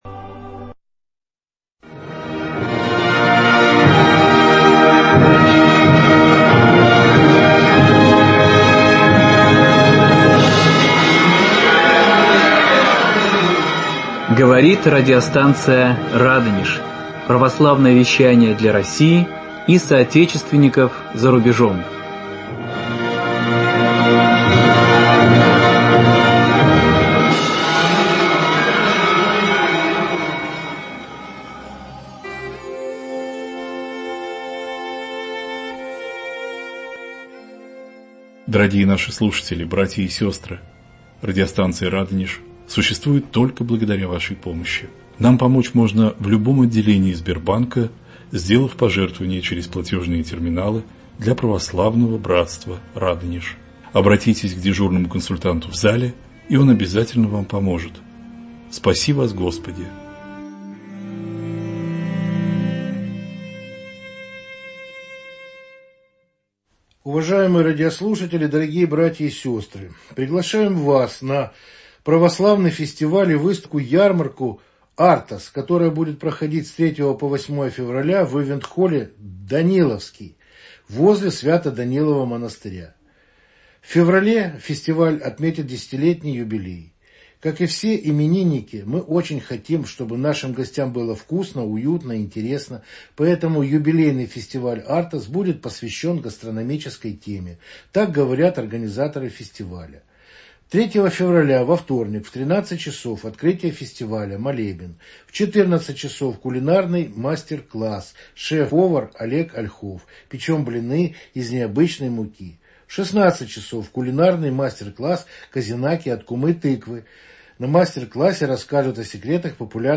В прямом эфире радиостанции "Радонеж"